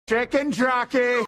Instant meme sound effect perfect for videos, streams, and sharing with friends.